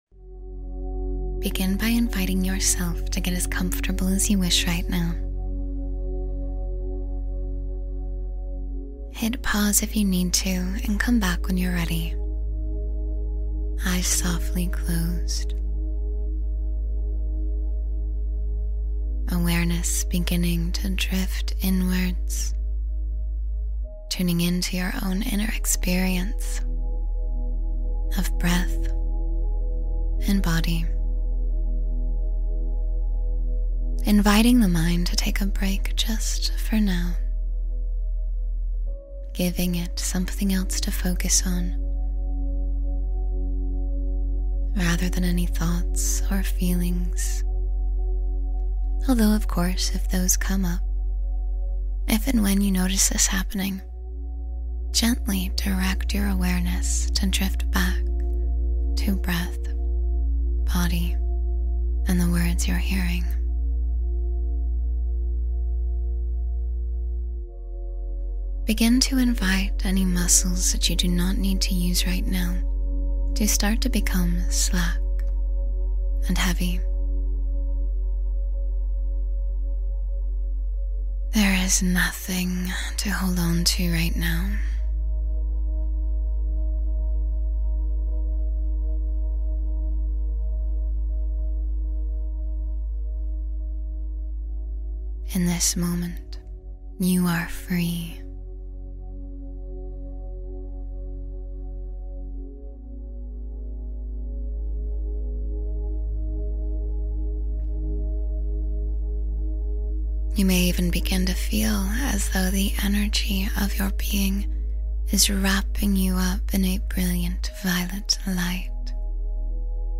Guided mindfulness exercises